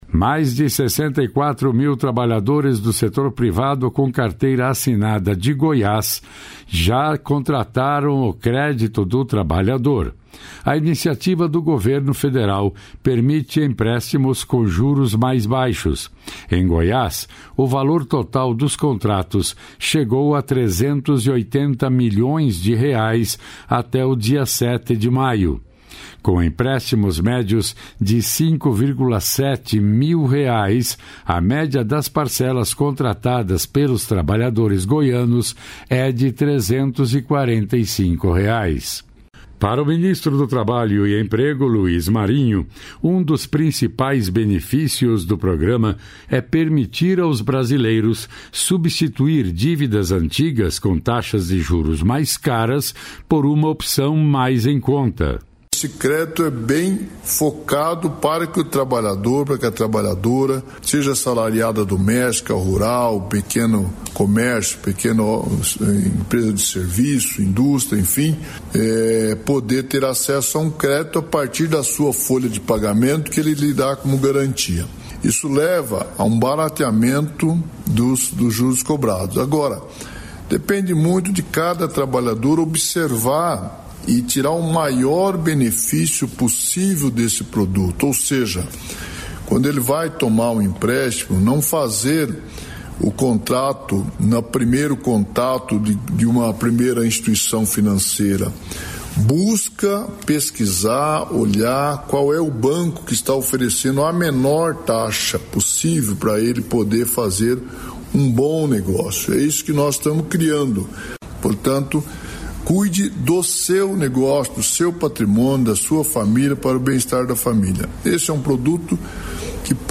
Convocação de Rede
09/12/23 - Pronunciamento do Ministro dos Direitos Humanos e da Cidadania Silvio Almeida